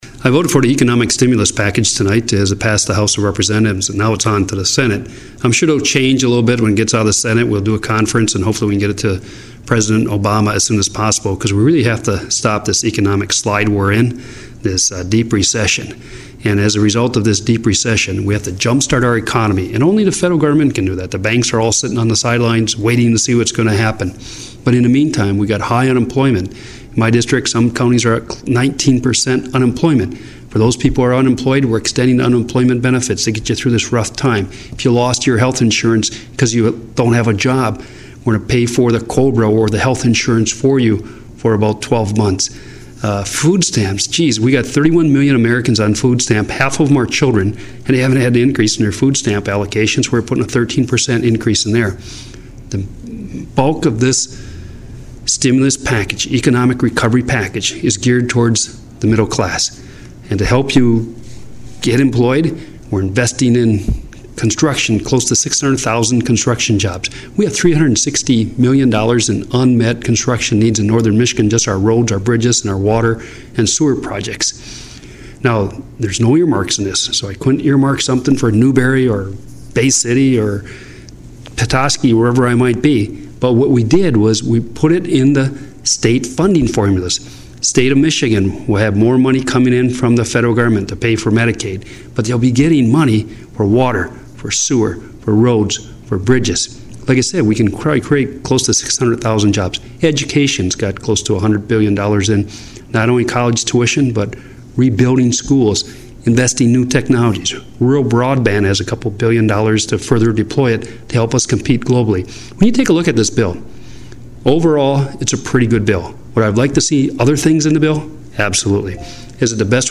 Congressman Bart Stupak – Comments on US House passage of the Economic Stimulus Package.